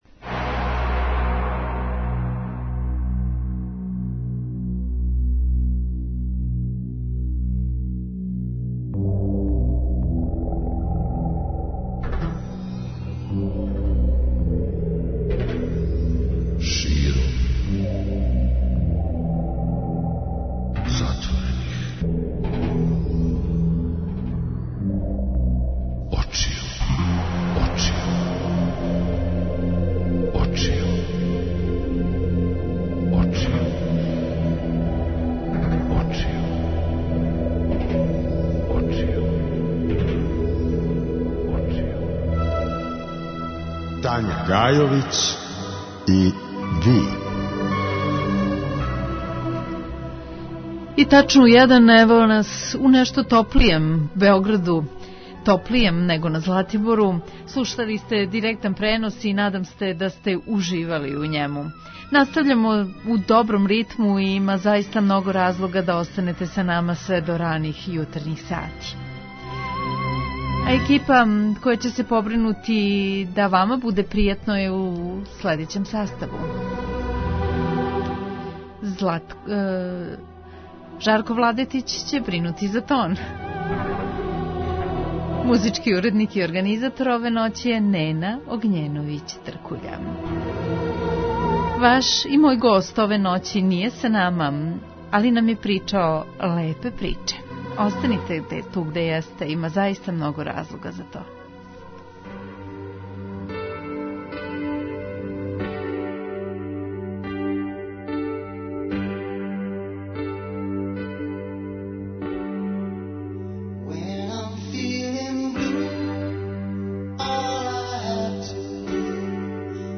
Ексклузивно гост: Златко Пејаковић, музичар
На недавно одржаном музичком фестивалу у Водицама (Хрватска) ексклузивно је за Ноћни програм Широм затворених очију говорио Златко Пејаковић .